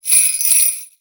Shaken Jingles.wav